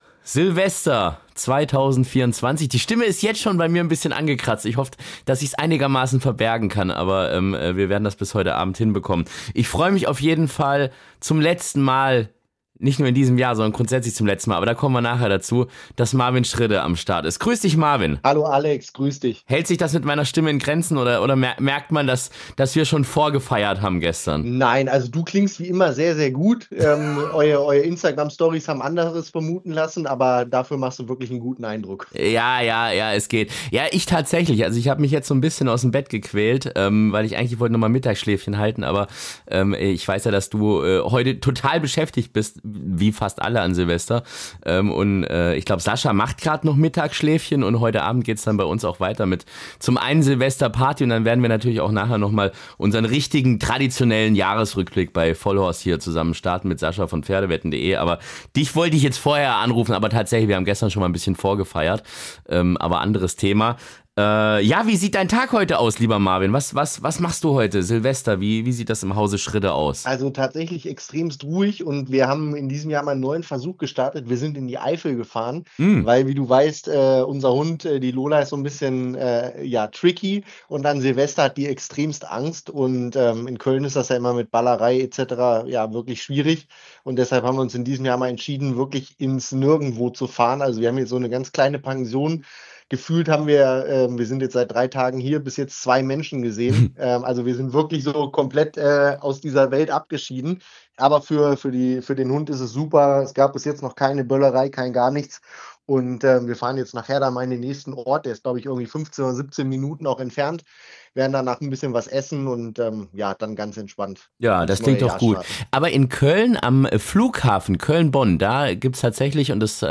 Tief verstörend und mit dem Duktus, wie Martin Semmelrogge Hitler-Dokus aus dem Off sprechen würde.